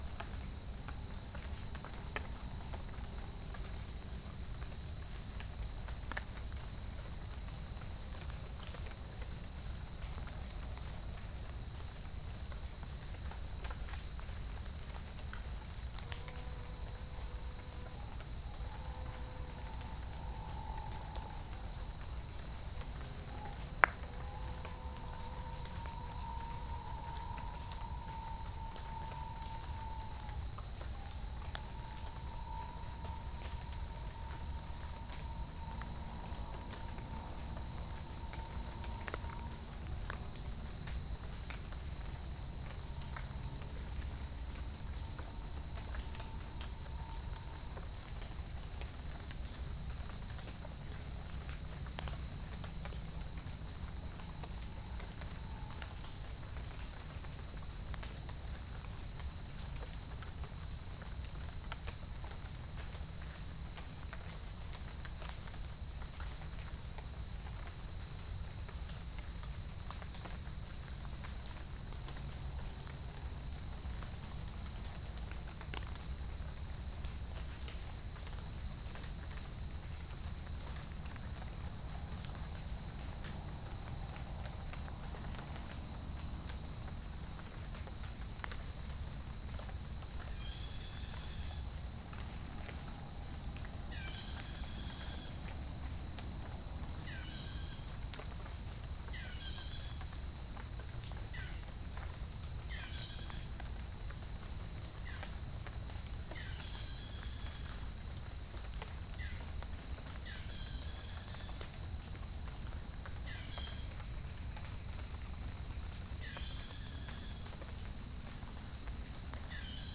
16513 | Geopelia striata 16533 | Pycnonotus goiavier
Halcyon smyrnensis
Orthotomus sericeus
Dicaeum trigonostigma